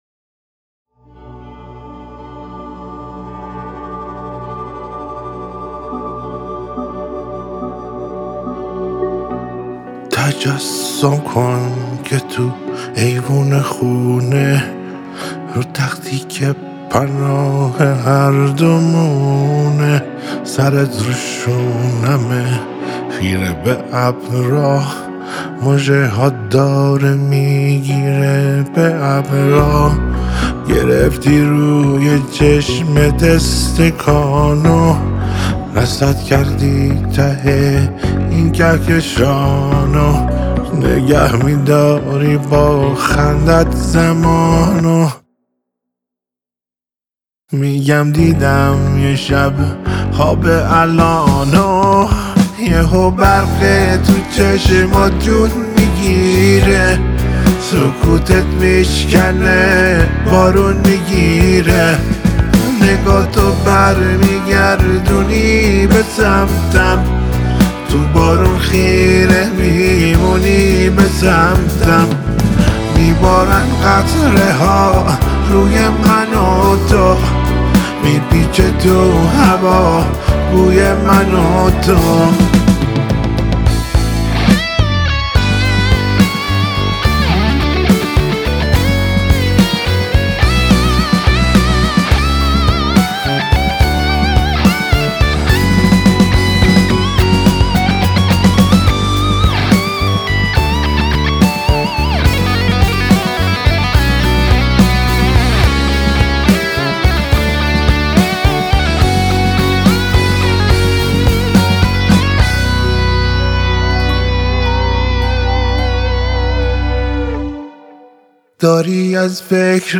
تنظیم، گیتار الکتریک